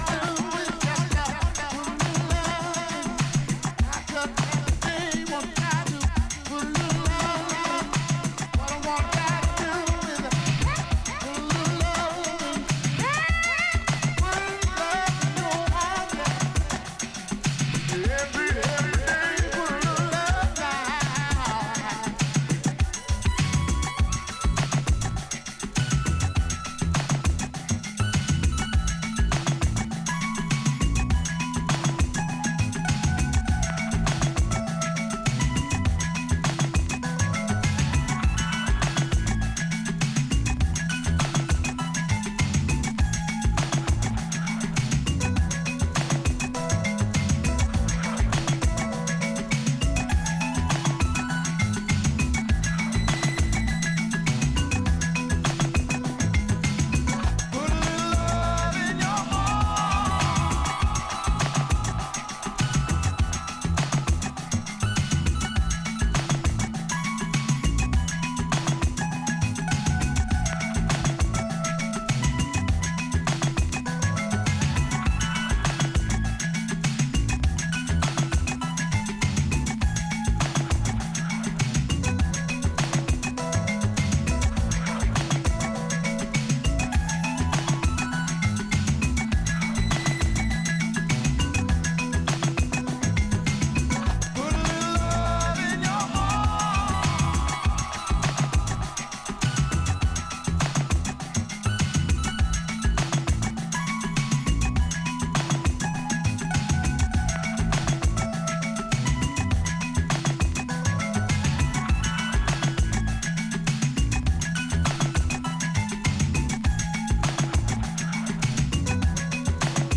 ここでは、シンセ・ポップやアフロ・トロピカル路線の音源をネタに用いながらゆるくてダビーなバレアリック・トラックを展開。